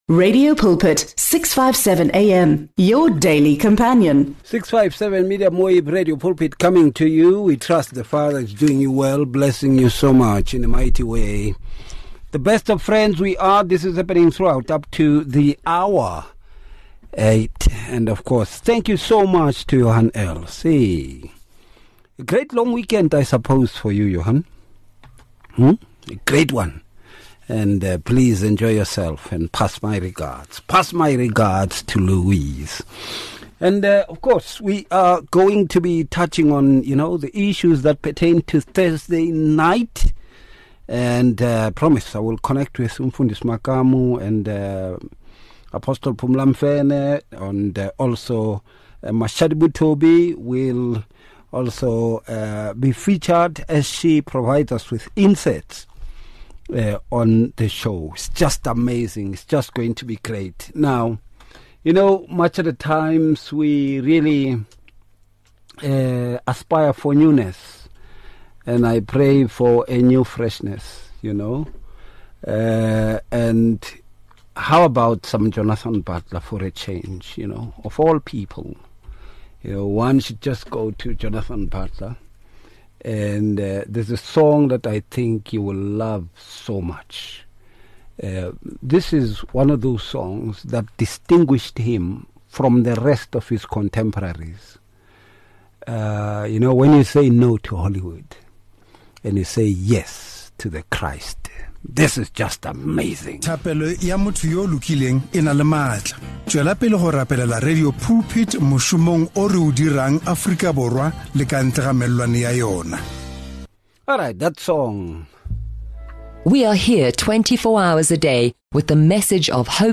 MultiMedia LIVE